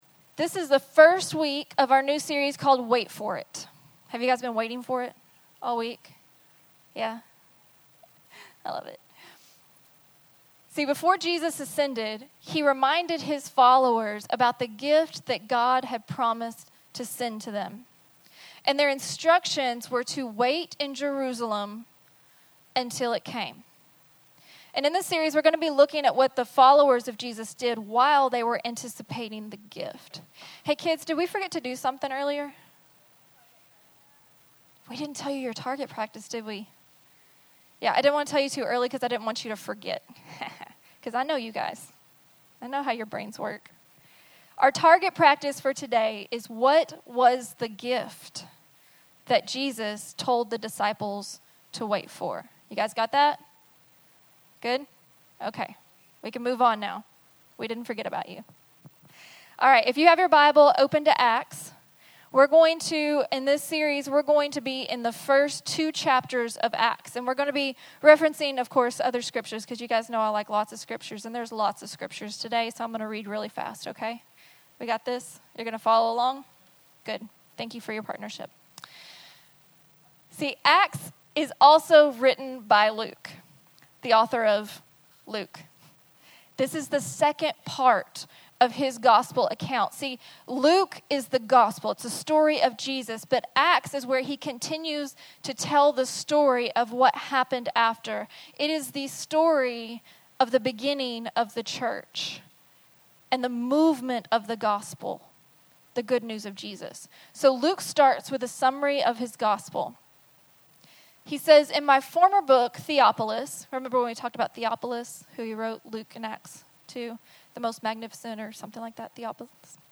Sermons | Project Community